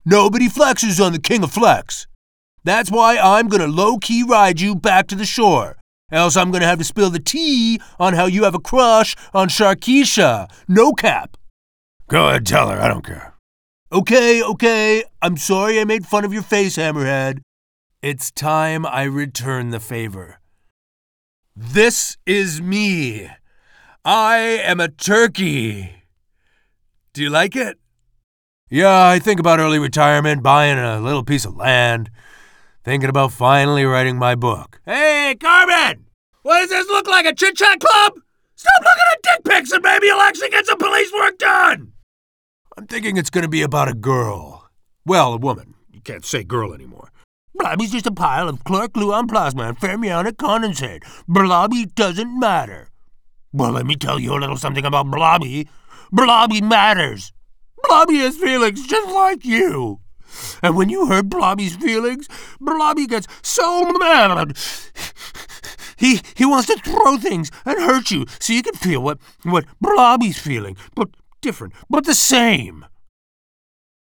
Englisch (Amerikanisch)
Tief, Natürlich, Unverwechselbar, Vielseitig, Warm
Persönlichkeiten